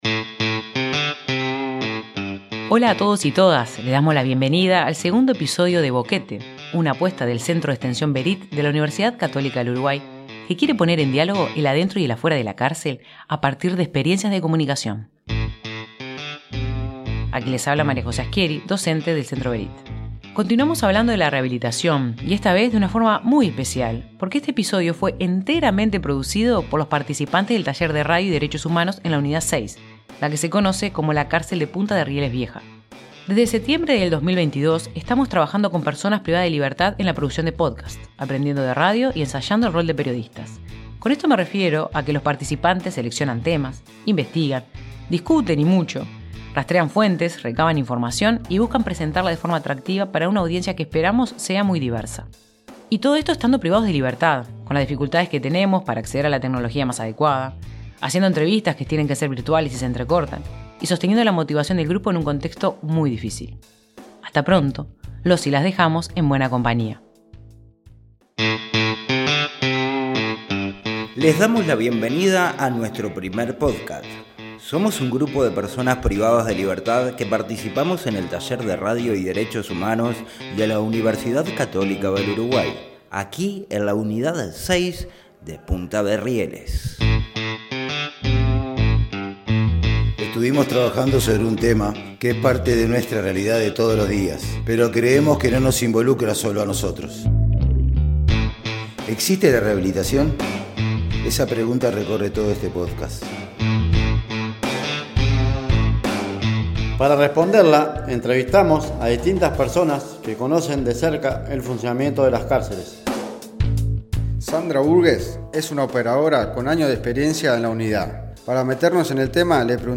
Boquete es un proyecto del Centro Berit para generar diálogos sobre lo que pasa adentro y afuera de la cárcel. En este capítulo volvemos a hablar de rehabilitación pero de una forma especial, porque fue enteramente producido por personas privadas de libertad del taller de Radio y Derechos Humanos que la UCU lleva adelante en la unidad 6 de Punta de Rieles. Ellos investigaron, discutieron y diseñaron la mejor forma de contar las buenas noticias y las cuentas pendientes para que la pena en cárcel cumpla su función rehabilitadora.